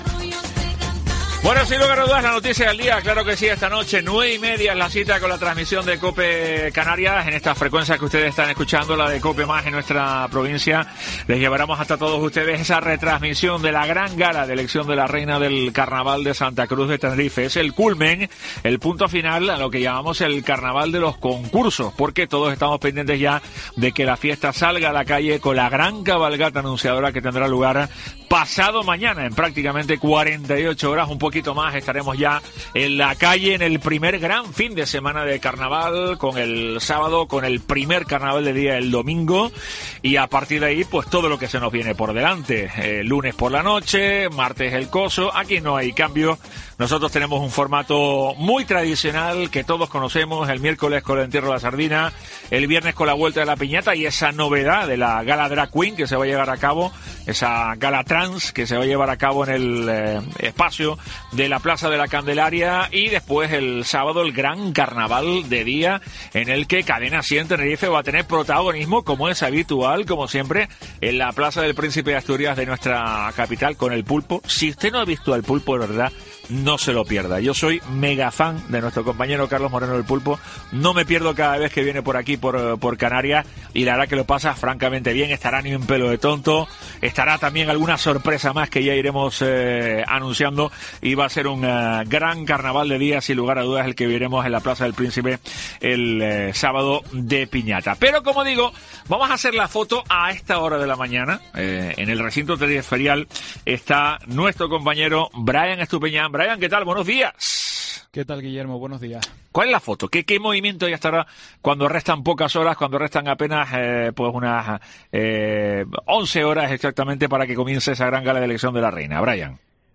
AUDIO: Nos desplazamos al Recinto Ferial para conocer con el concejal de Fiestas cómo se prepara una de las grandes noches del Carnaval de Santa Cruz